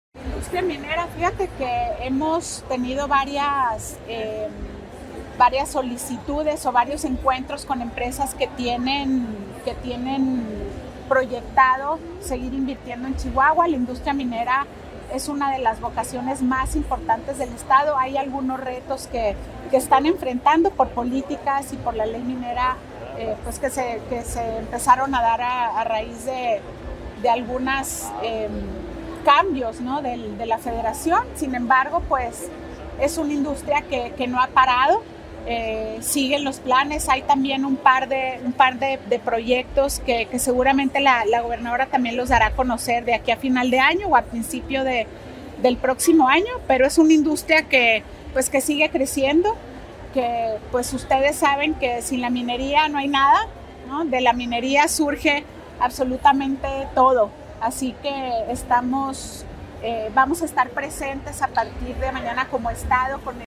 AUDIO: MARÍA ANGÉLICA GRANADOS, SECRETARÍA DE INNOVACIÓN Y DESARROLLO ECONÓMICO (SIDE)